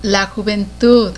Introduction To Spanish Nouns -- People, Places and Things Whenever you see this speaker symbol next to an underlined word, you can click on the speaker or the underlined word to hear the word spoken by a native Spanish speaker.
joventud.wav